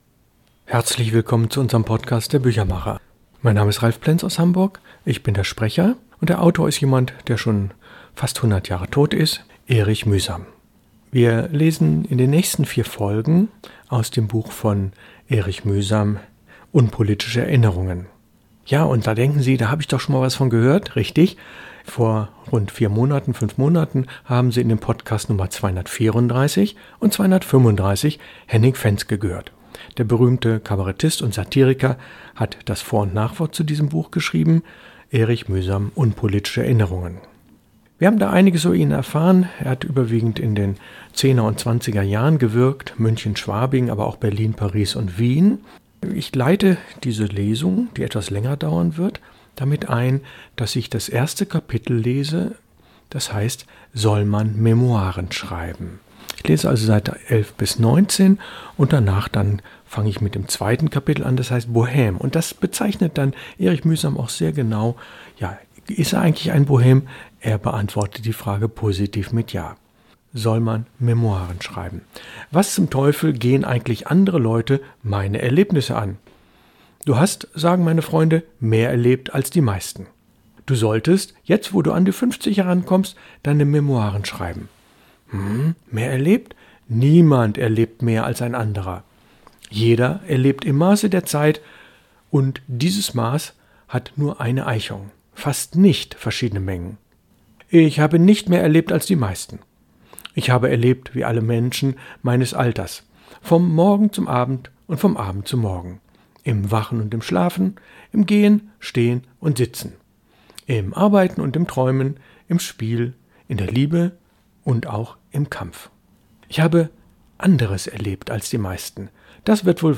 Lesung aus: Erich Mühsam – Unpolitische Erinnerungen, Folge 1 von 4